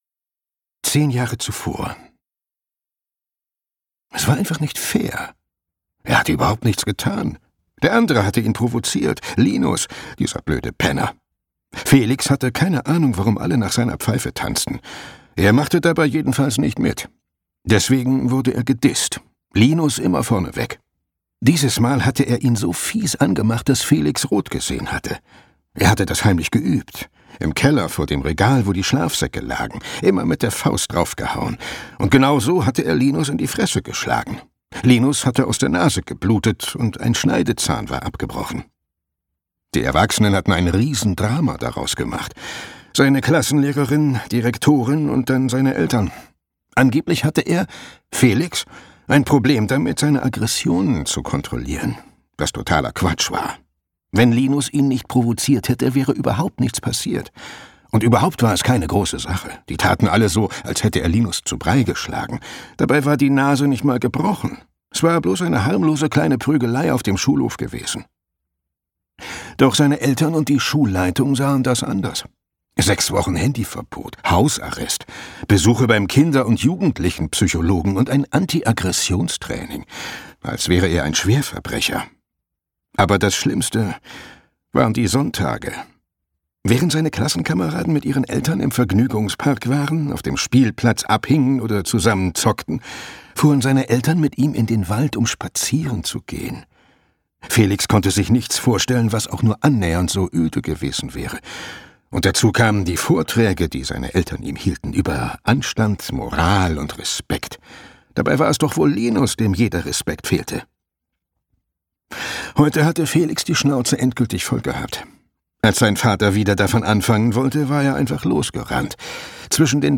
Todestal - Daniel Holbe, Ben Tomasson | argon hörbuch
Gekürzt Autorisierte, d.h. von Autor:innen und / oder Verlagen freigegebene, bearbeitete Fassung.